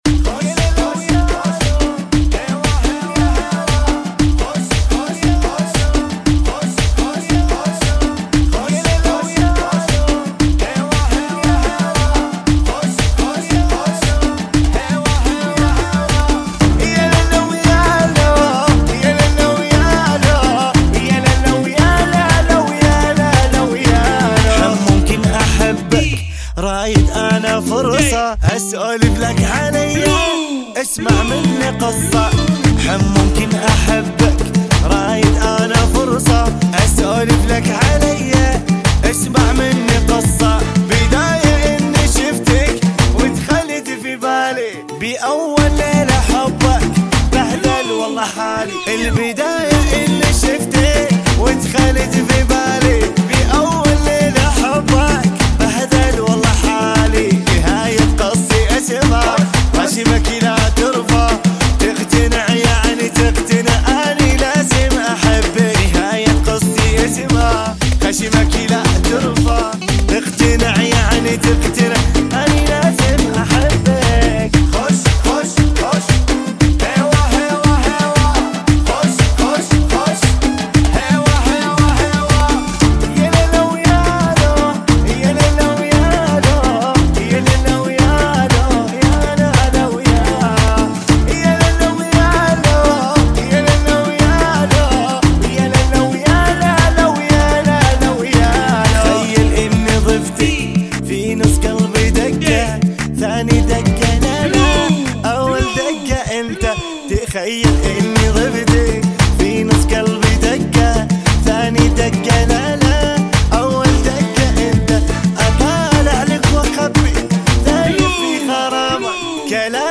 116 Bpm